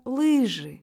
Trois consonnes sont toujours dures: Ц, Ж, Ш